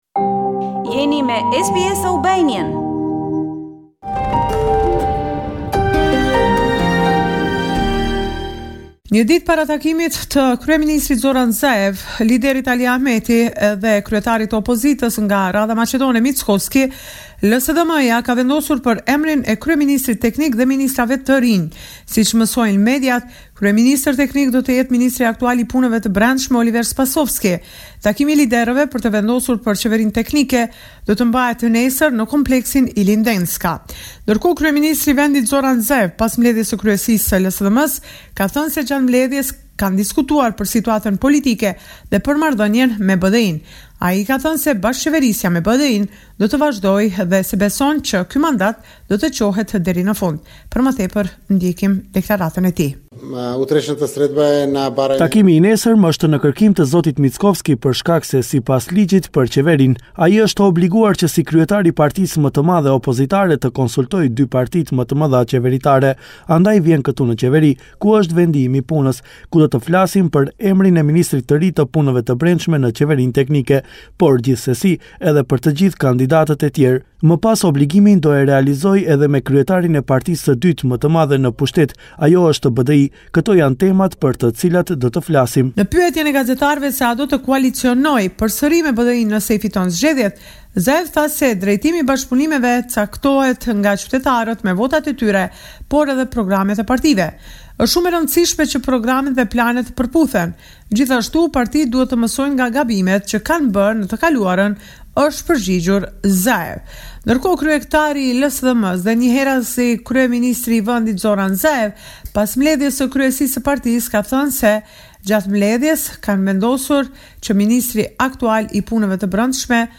This is a report summarising the latest developments in news and current affairs in North Macedonia.